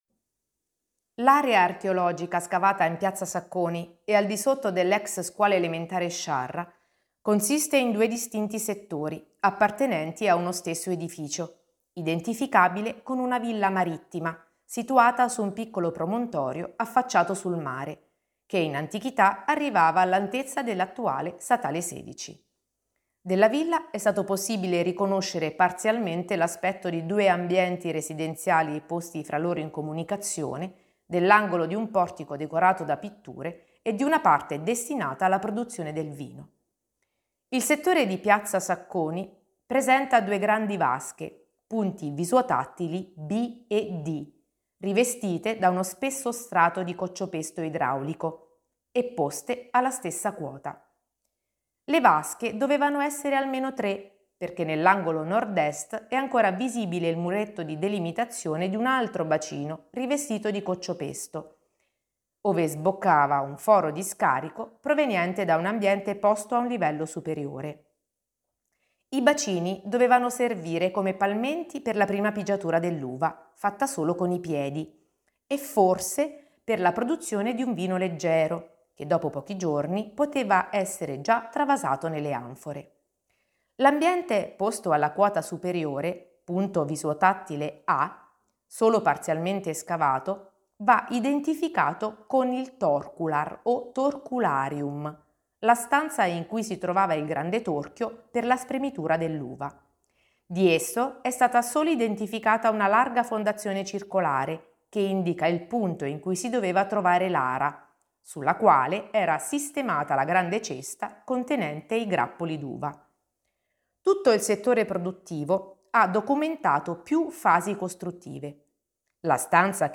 RIPRODUCI L'AUDIOGUIDA COMPLETA RIPRODUCI FERMA oppure LEGGI LA STORIA GUARDA IL VIDEO LIS